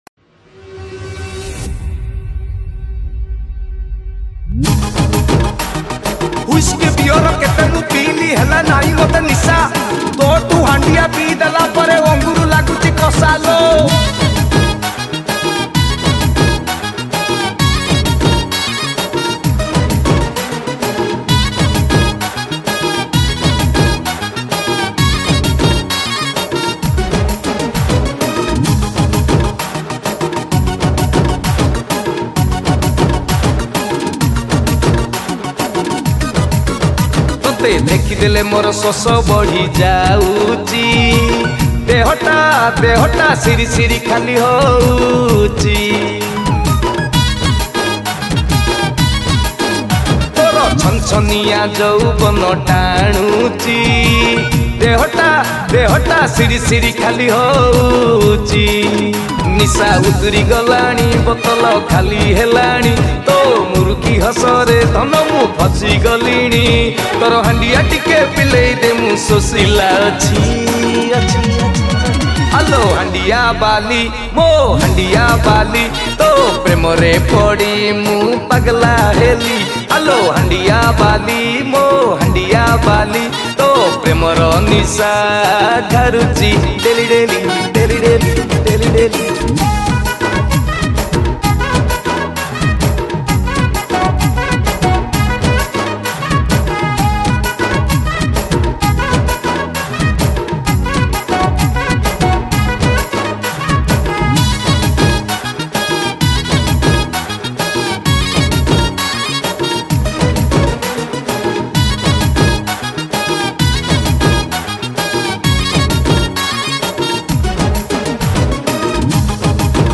Keyboard and programming